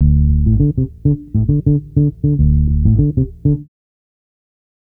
Bass Lick 34-11.wav